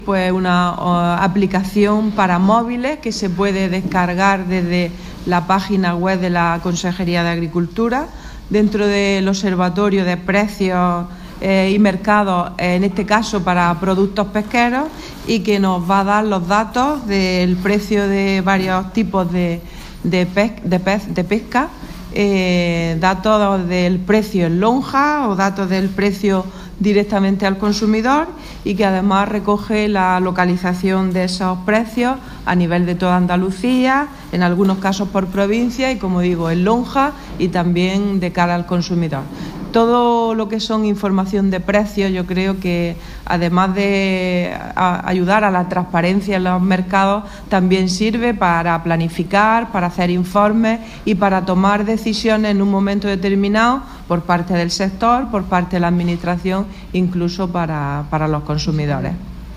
La consejera, durante la rueda de prensa en Almería.
Declaraciones consejera Plan Gestión Pesca Mediterráneo (2)